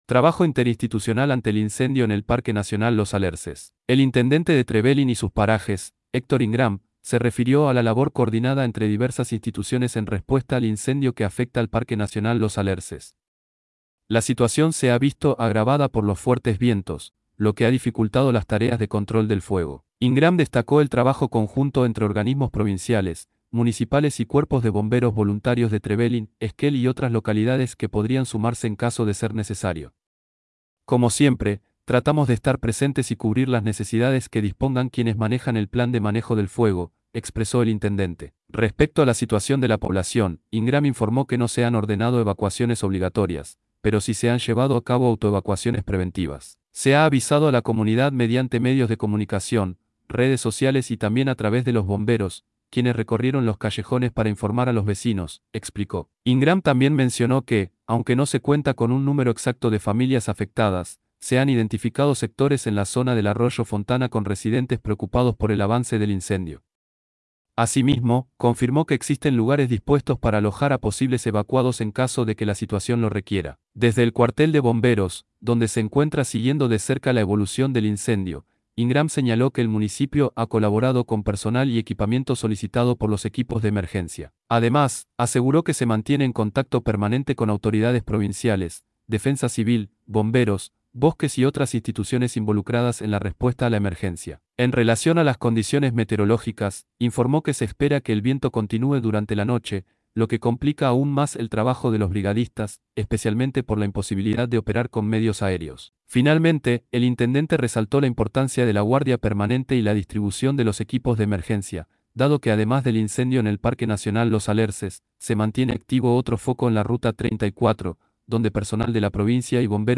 El intendente de Trevelin y sus Parajes, Héctor Ingram, se refirió a la labor coordinada entre diversas instituciones en respuesta al incendio que afecta al Parque Nacional Los Alerces.
Desde el cuartel de bomberos, donde se encuentra siguiendo de cerca la evolución del incendio, Ingram señaló que el Municipio ha colaborado con personal y equipamiento solicitado por los equipos de emergencia.